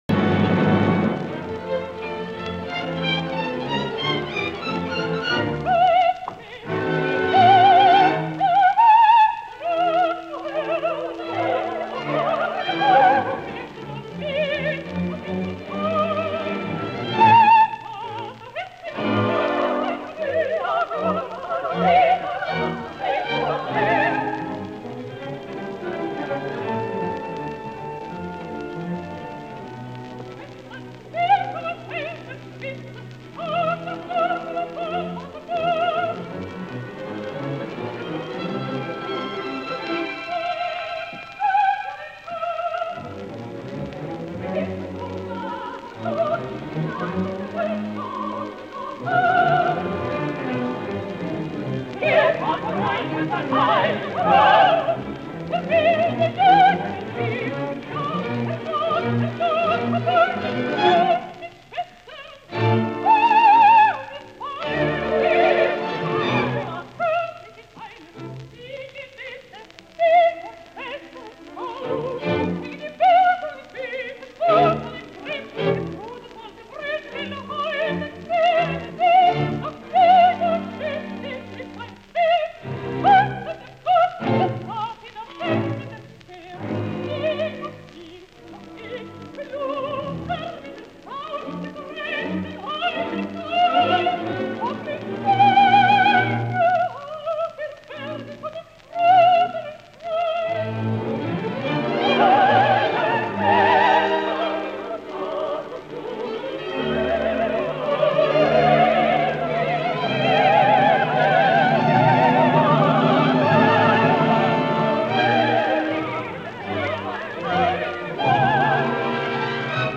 Lo stesso stile veniva praticato, infatti a Londra al Covent Garden e lo documentiamo con uno stralcio di Walkure diretta da Furtwaengler nella capitale del Regno Unito anno 1937 e lo potremmo fare anche con riferimenti ai teatri sud americani, dove spesso i cantanti tedeschi eseguivano in italiano le opere wagneriane.